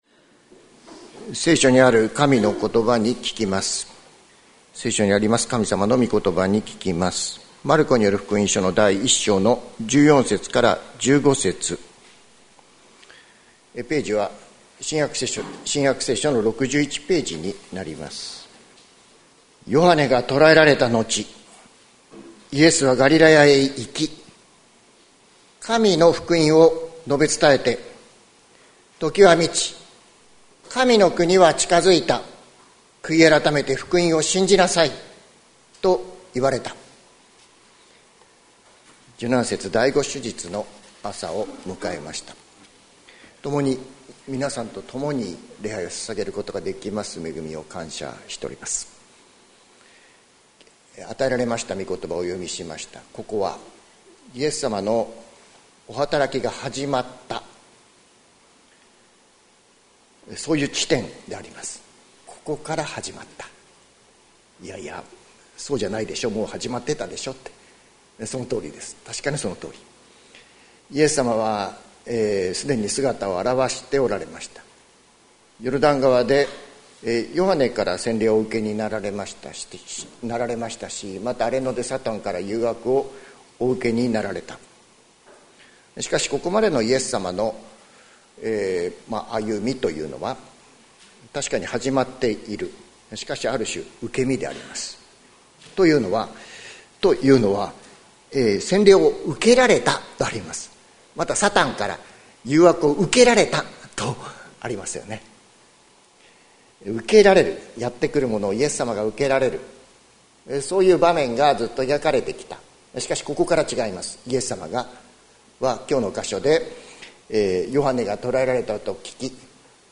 2025年04月06日朝の礼拝「神の国は近づいた」関キリスト教会
説教アーカイブ。